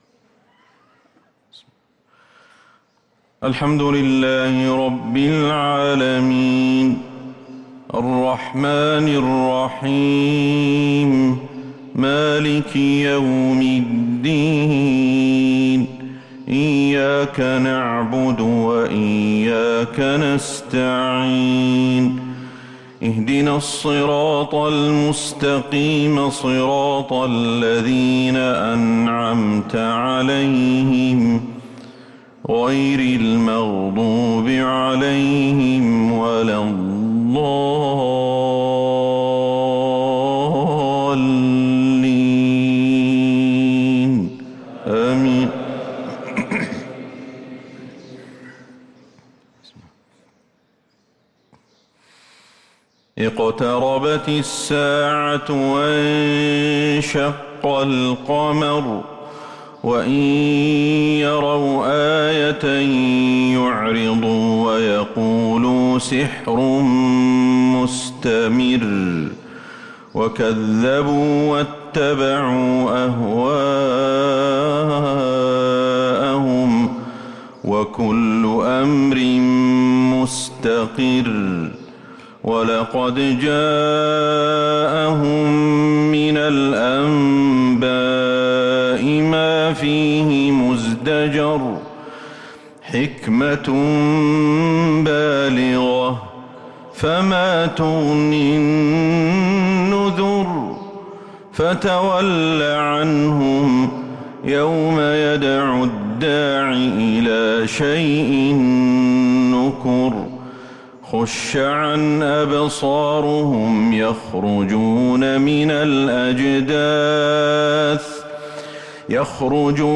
صلاة الفجر للقارئ أحمد الحذيفي 7 ربيع الآخر 1444 هـ